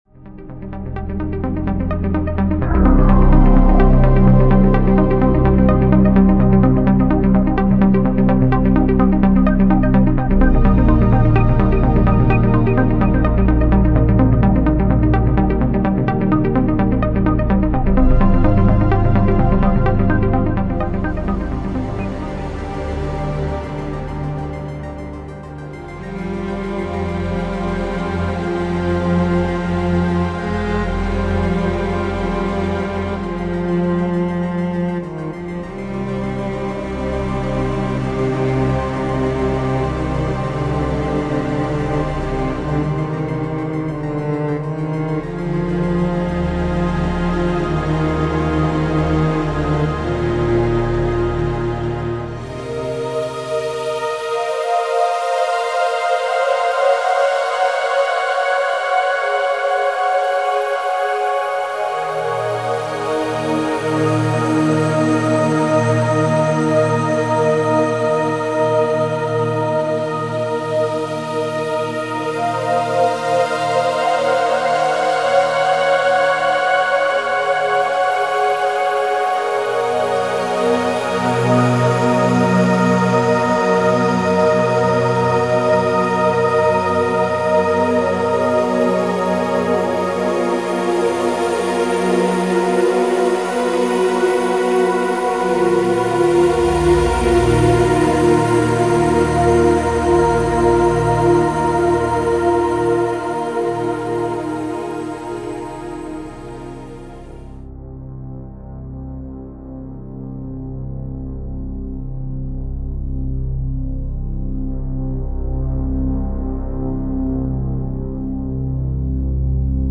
Je vous ai fait un petit montage avec des extraits de ce projet vintage.
Comme je vous l'avais dit, c'est très orienté planant et années 70, Klaus Schulze ...
Les morceaux seront plus longs et plus monotones ... donc assez chiants en définitive ...
Le choix des sons et les ambiances sont phénoménales, on pense parfois à des reférences légendaires, mais ça reste toujours suffisament personnel.